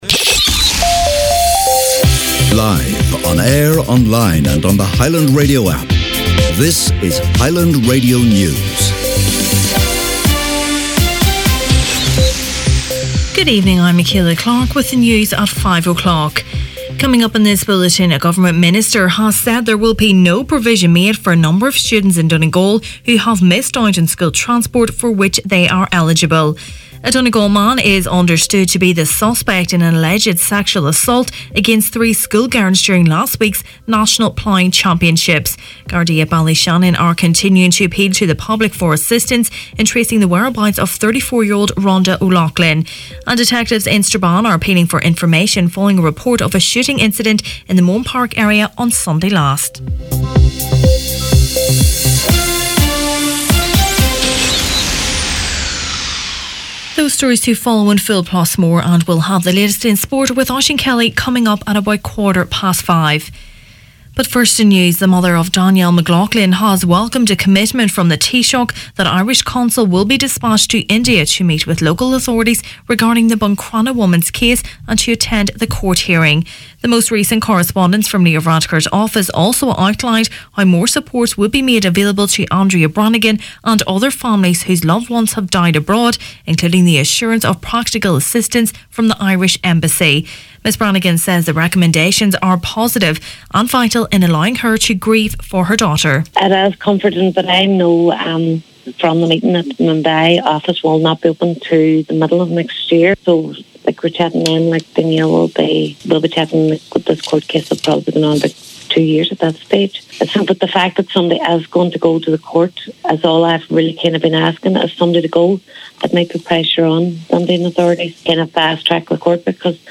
Main Evening News, Sport and Obituaries Wednesday September 26th